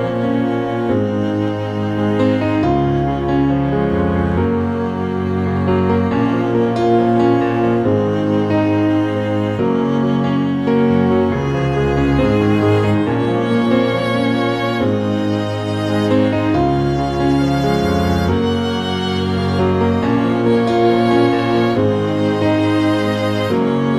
Multiplex Lead Version